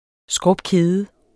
Udtale [ ˈsgʁɔbˈkeːðə ]